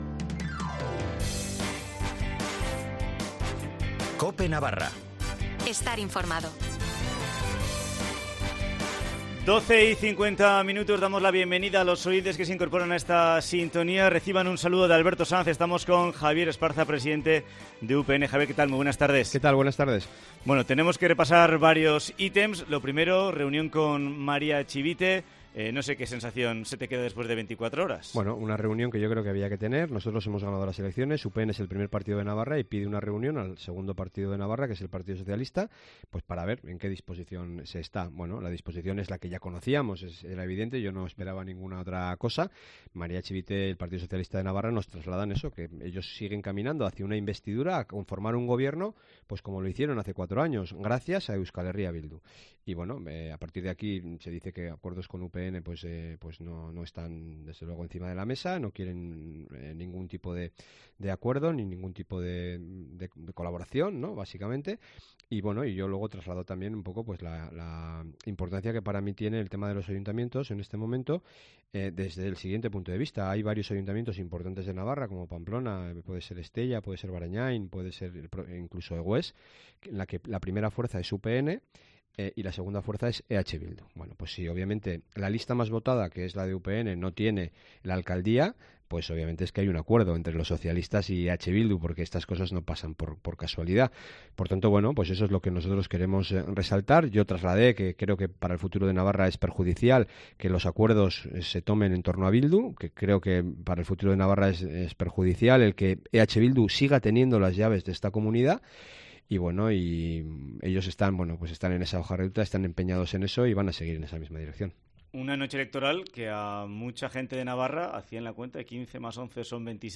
Entrevista con Javier Esparza, presidente de UPN